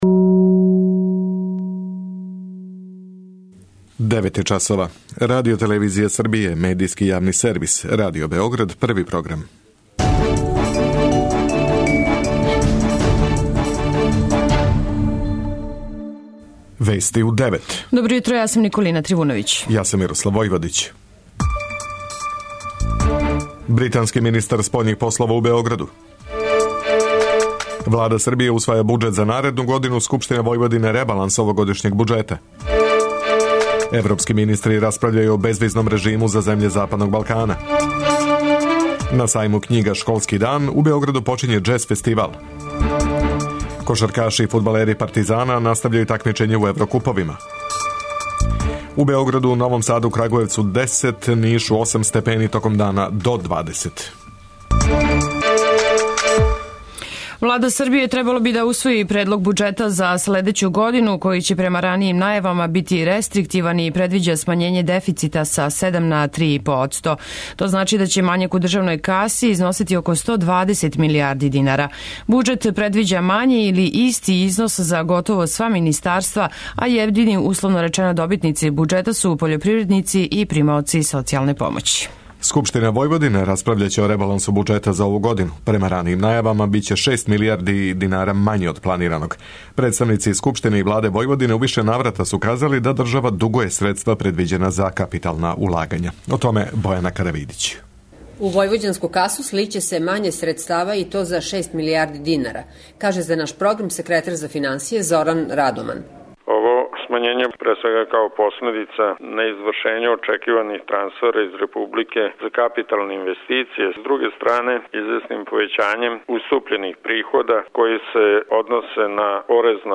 Уредници и водитељи
преузми : 10.29 MB Вести у 9 Autor: разни аутори Преглед најважнијиx информација из земље из света.